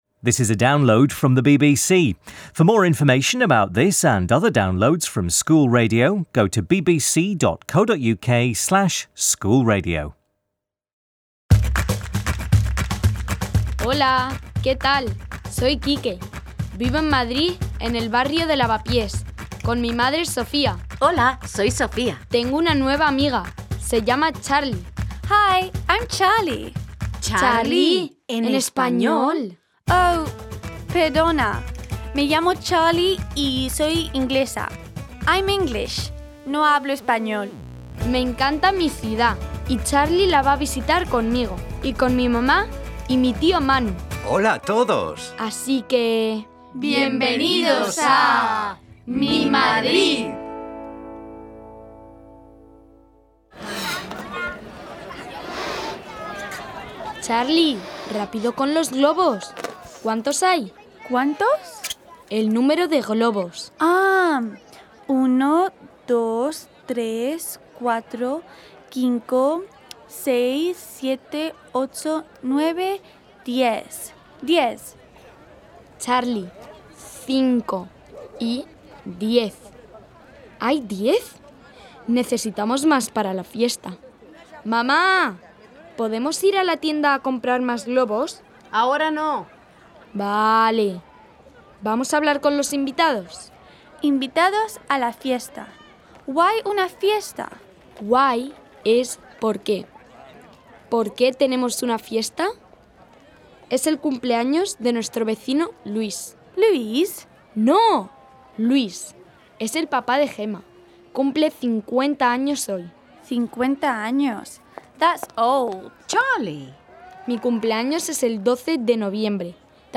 Charlie and Quique prepare for a birthday party. Sofía tells them a story about '¡La Tomatina!' - the annual tomato festival which takes place in the Valencian town of Buñol every August. Uncle Manu sings a song to celebrate - '¡La Fiesta!'